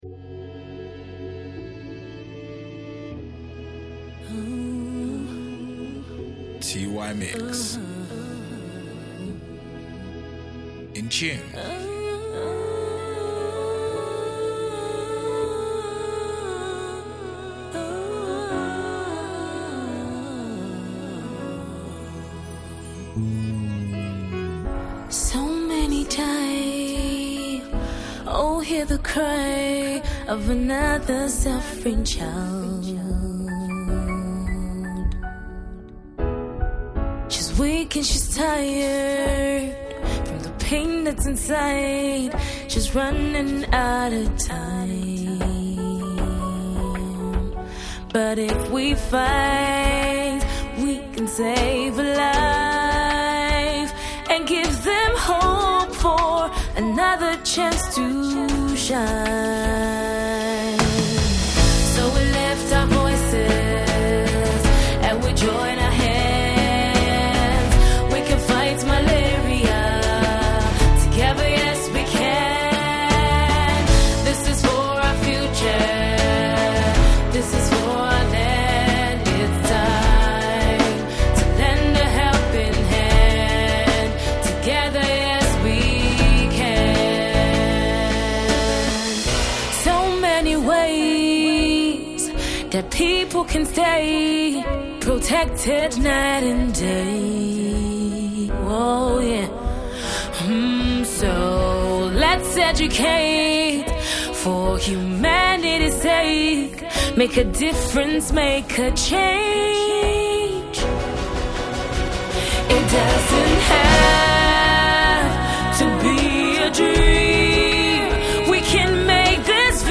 inspirational new tune